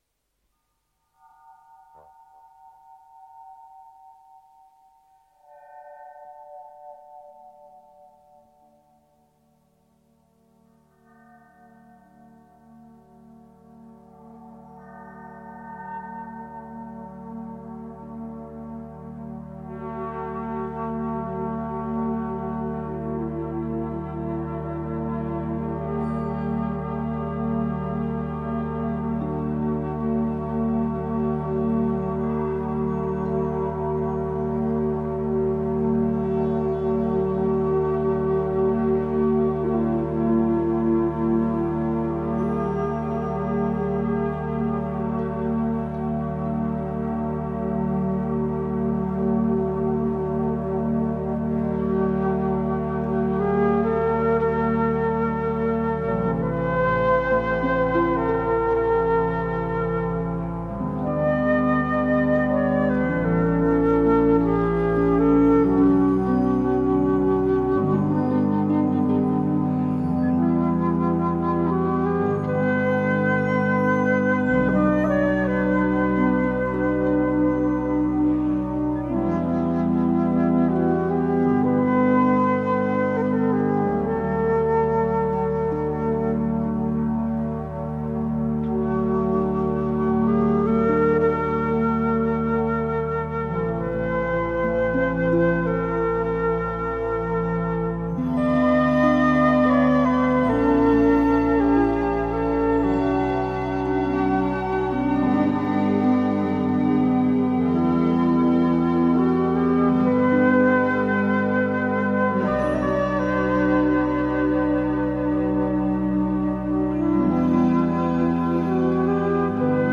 mini moog, bells and piano, English prog band
quietest tune
progressive rock